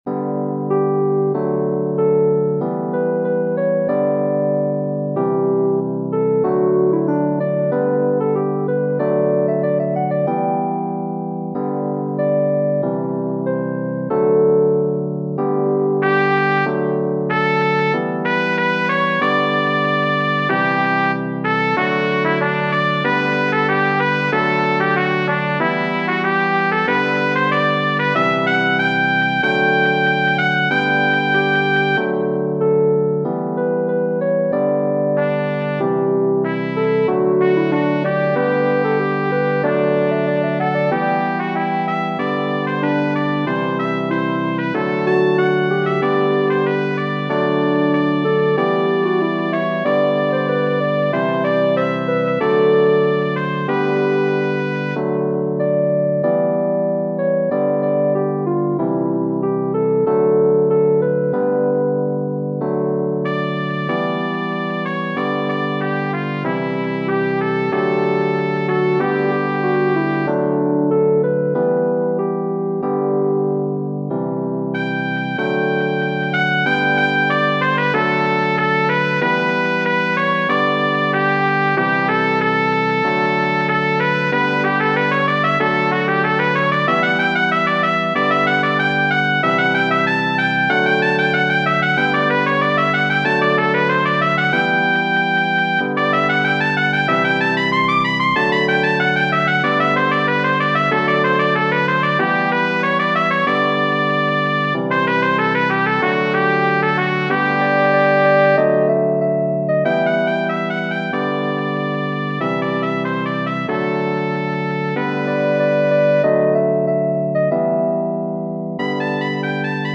Genere: Barocco